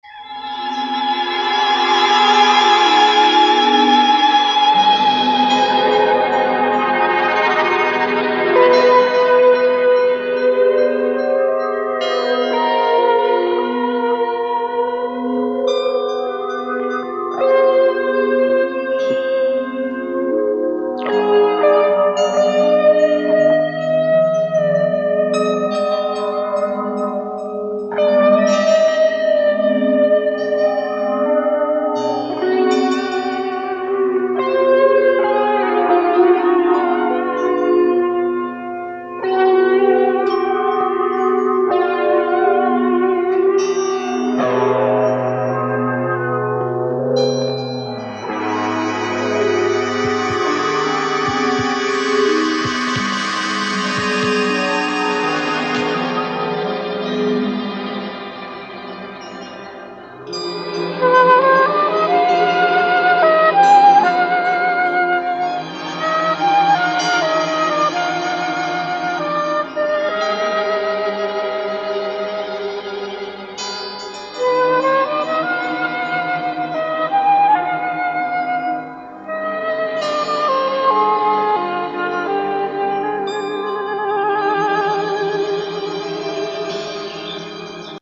И по звуку это слышно.